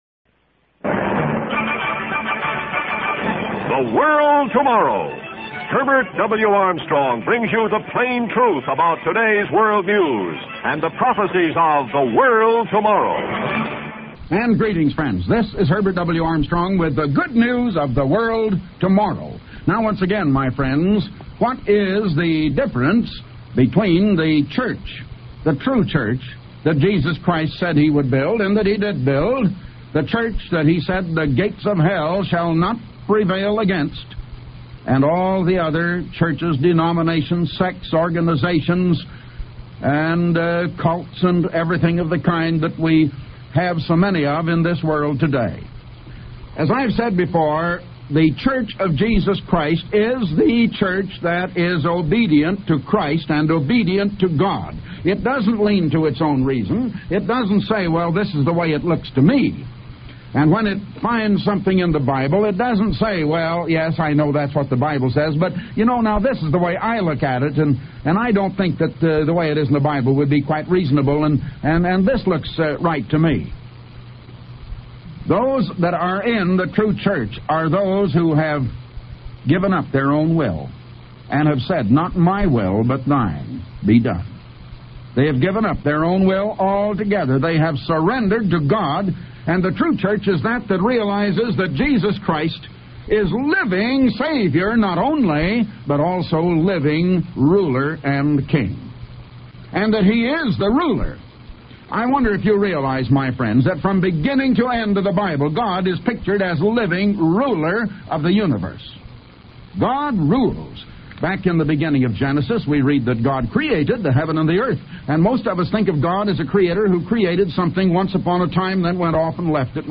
Type: Radio Broadcast